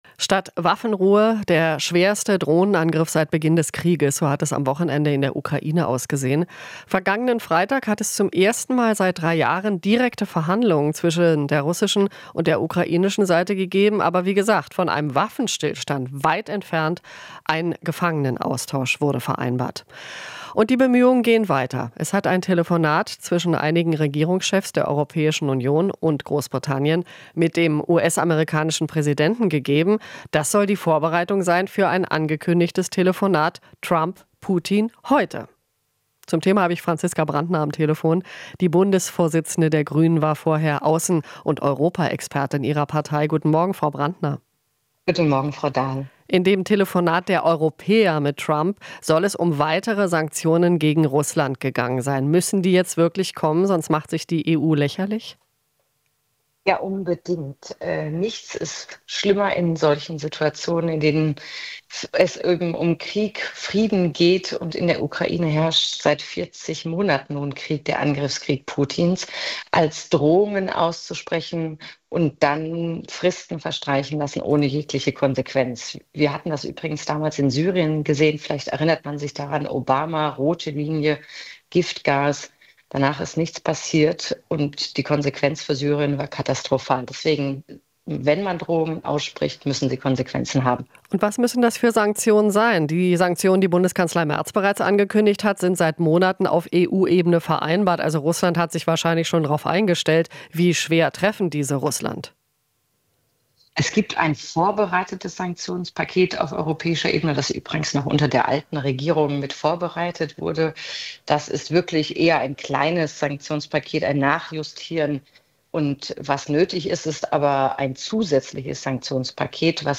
Interview - Brantner (Grüne) ruft Europäer zur Geschlossenheit gegenüber Trump auf